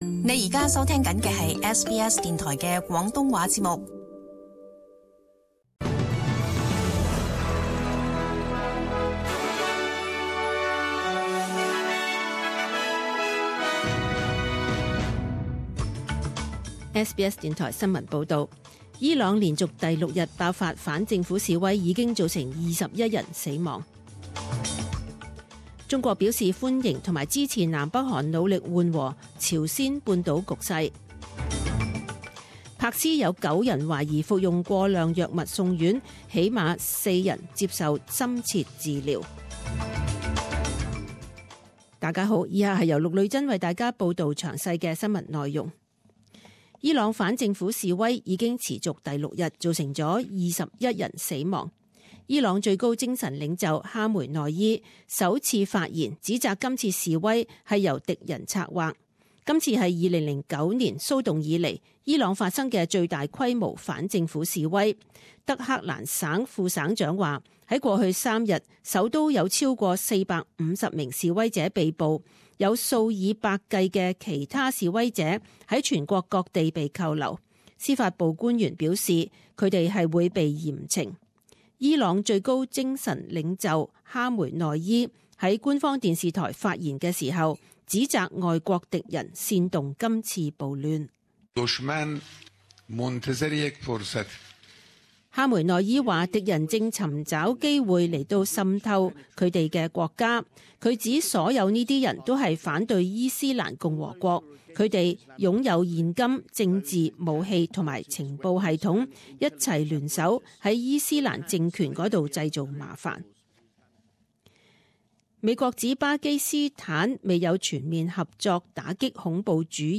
十点钟新闻报导 （一月三日）